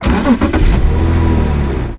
CAR_Start_Engine_Mixed_stereo.wav